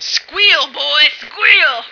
F1squeel.ogg